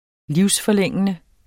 Udtale [ ˈliwsfʌˌlεŋˀənə ]